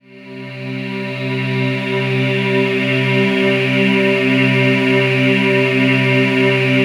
DM PAD2-24.wav